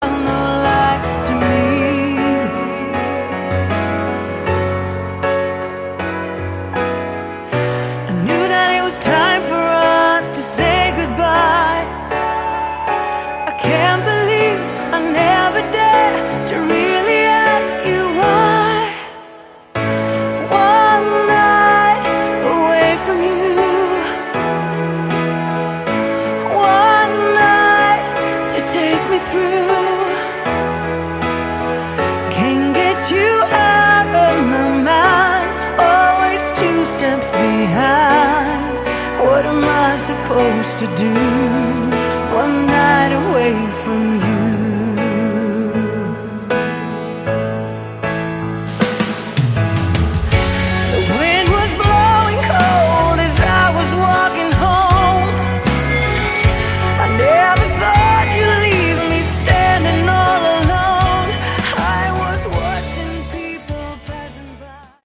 Category: Melodic Rock
lead vocals
keyboards
lead guitars, backing vocals
drums
bass